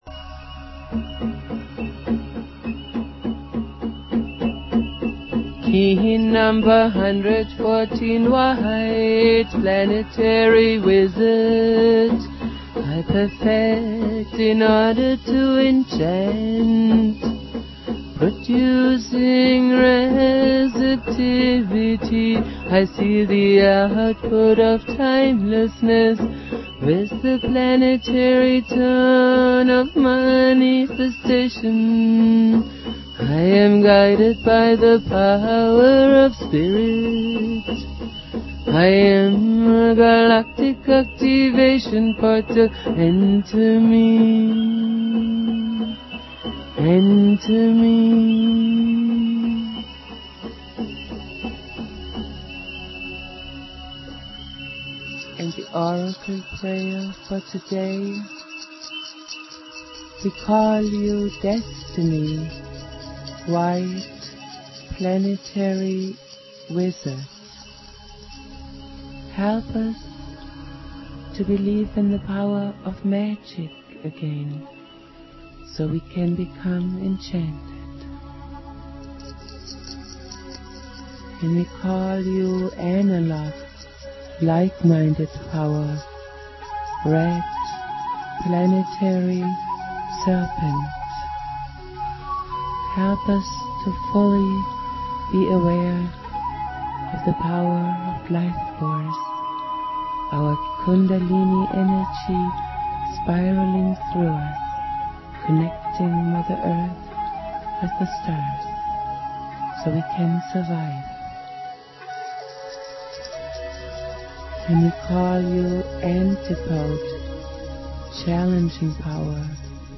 Prayer
Jose's spirit and teachings go on Jose Argüelles playing flute.